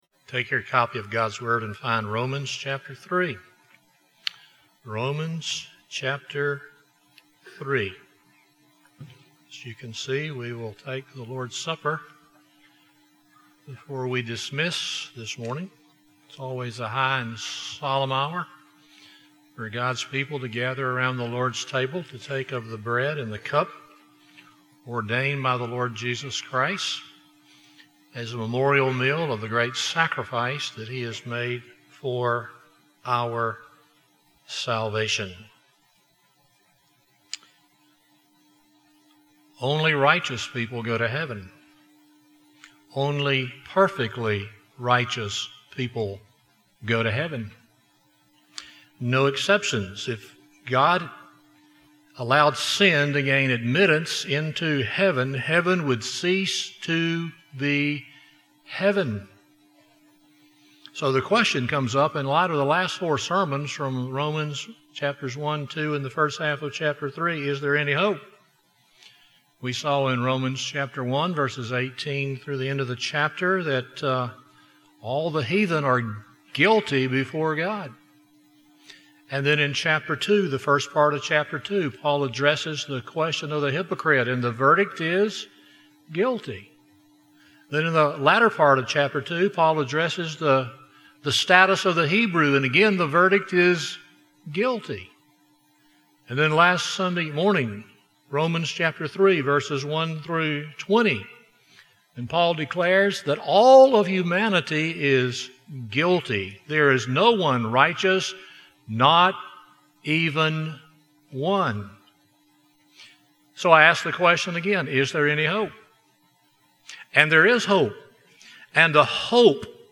Romans 3:21-31 Service Type: Sunday Morning Defining Justification